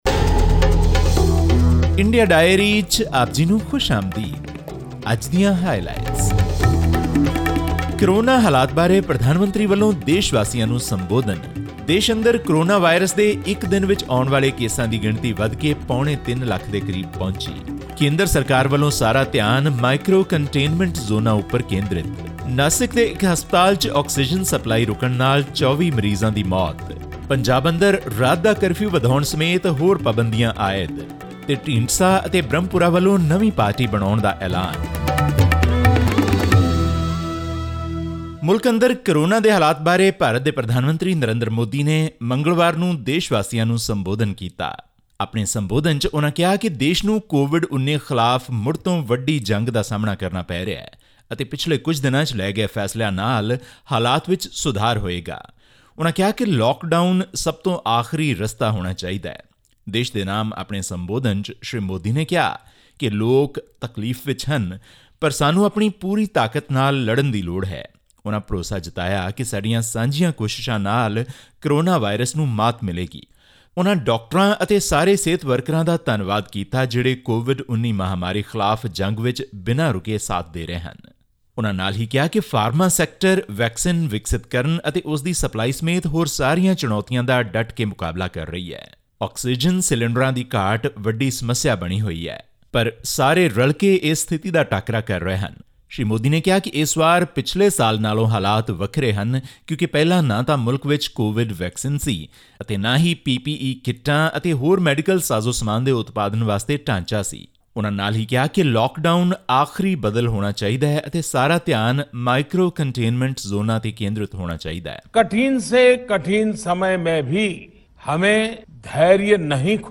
All this and more in our weekly news bulletin from India.